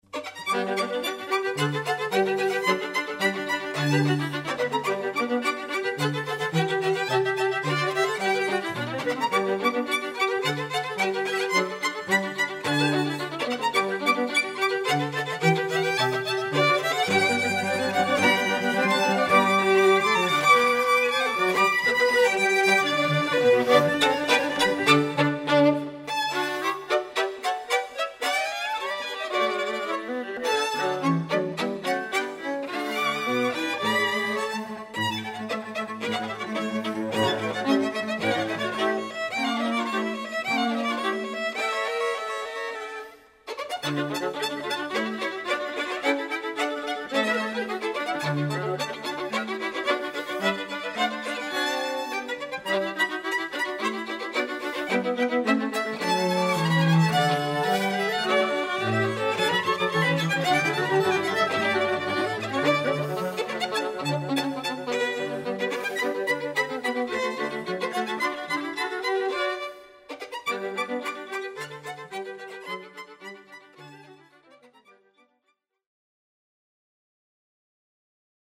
(Two Violins, Viola, & Cello)